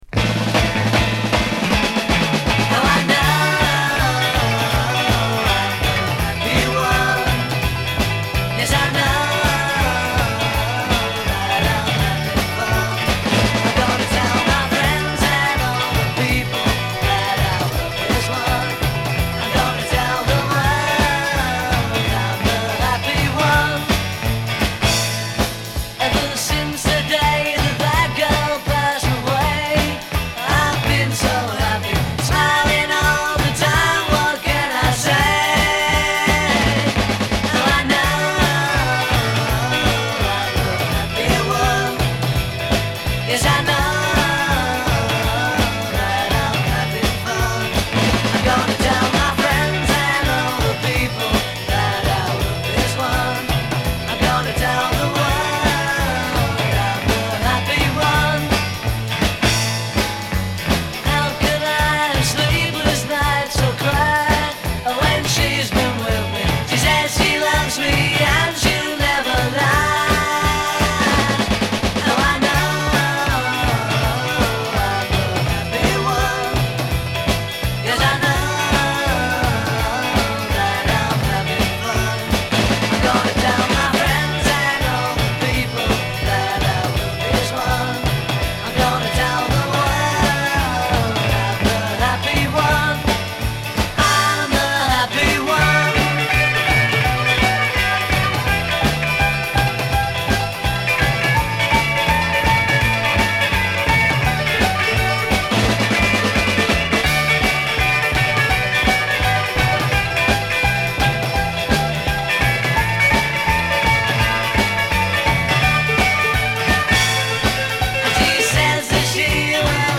Genre: Pop, Rock
Style: Power Pop